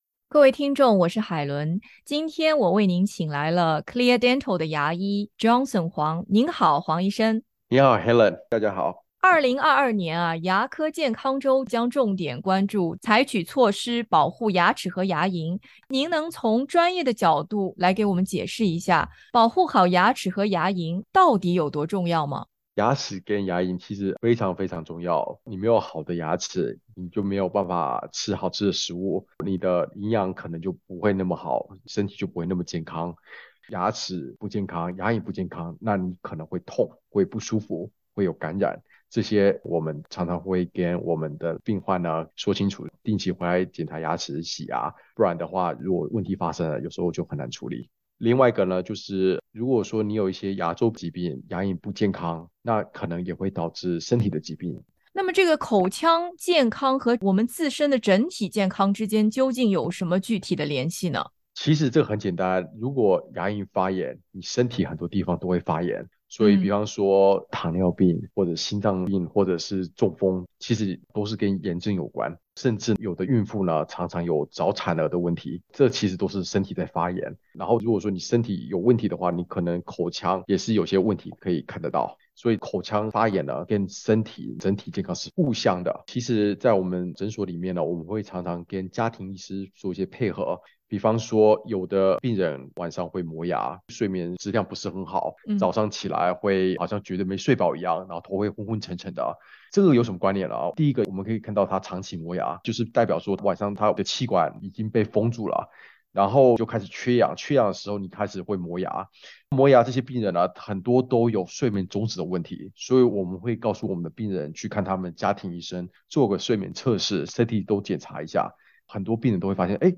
2022牙科健康周专访：保护好我们的牙齿和牙龈